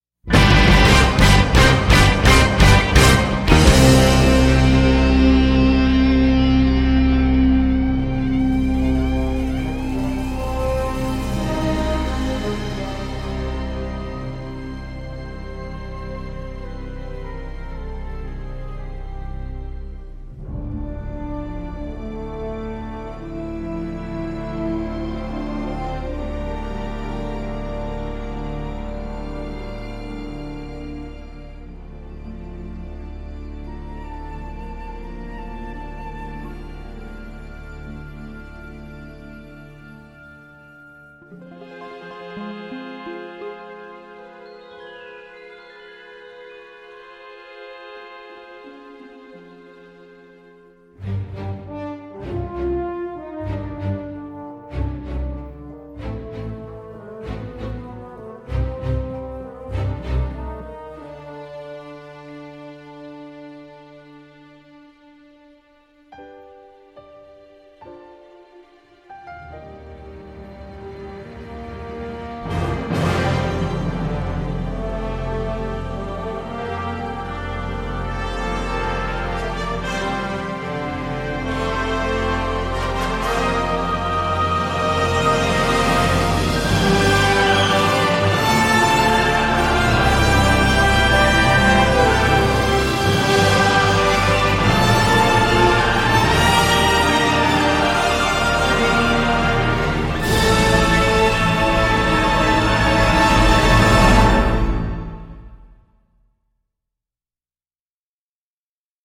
pop, funk, techno, country, rock et classique
rock, synth-pop, emphase orchestrale…
easy listening 90’s, Hard FM, hip hop west coast…
Plus marqué rock, ce score reste fun, sans être mémorable.
Electro-orchestrale dans son ensemble